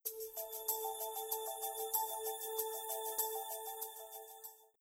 Exempel på ringsignaler: Ring RingRing Soft Concise Tranquil Klicka på bilderna för att förstora
soft